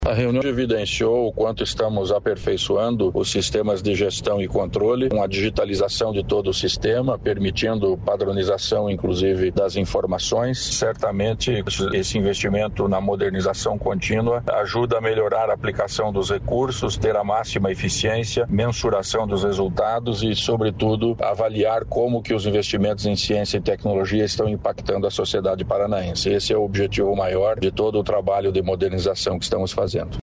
Sonora do secretário da Ciência, Tecnologia e Ensino Superior, Aldo Bona, sobre avanços no Fundo Paraná de fomento científico e tecnológico